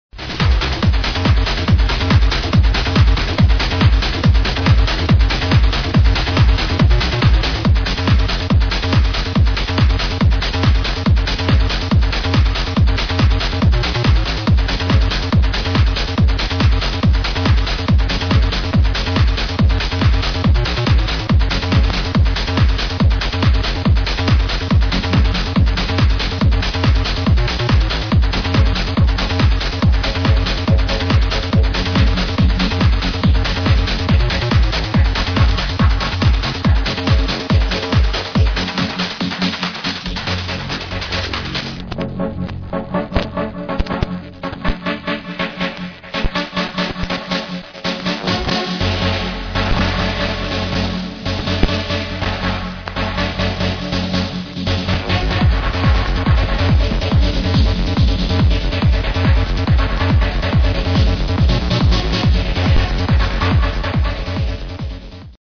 sounds like a trance remix
Trance mix